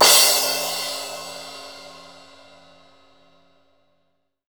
Index of /90_sSampleCDs/East Collexion - Drum 1 Dry/Partition C/VOLUME 001
CRASH009.wav